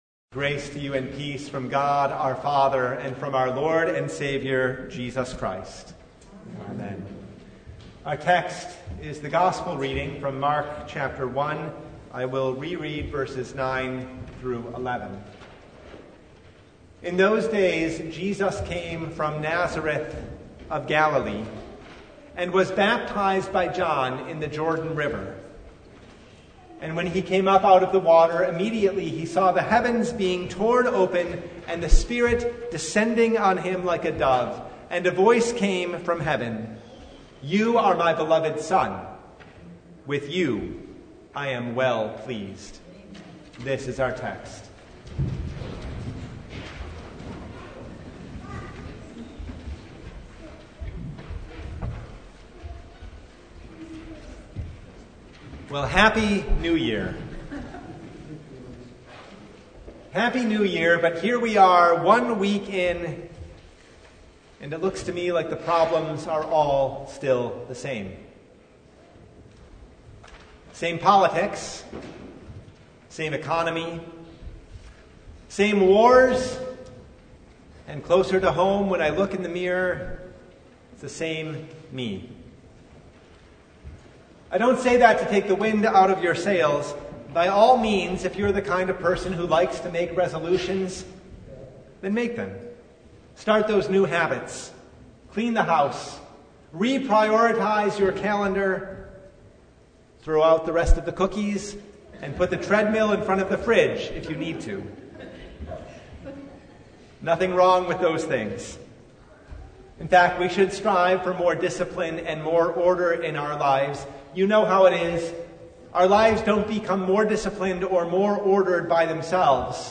Sermon from Baptism of Our Lord (2023)
Sermon Only